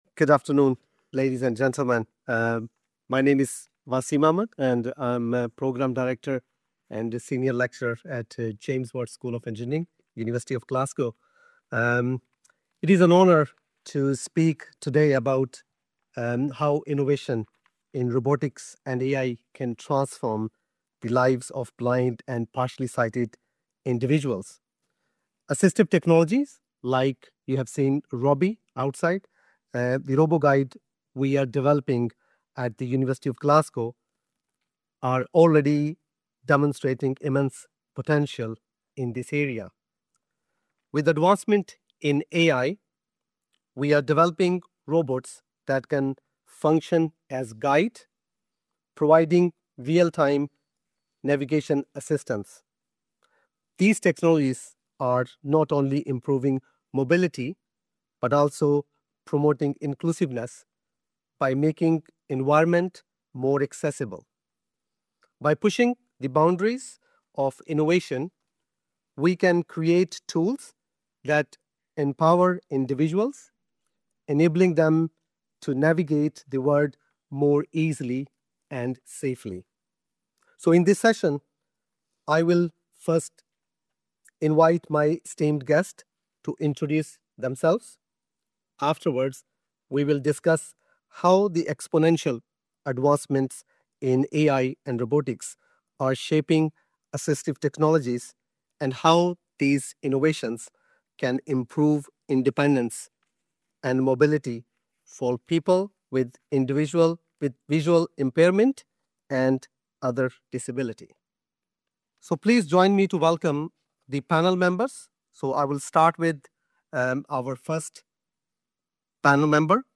Shaping the Future: Robotics session from day one of RNIB Scotland's Inclusive Design for Sustainability Conference.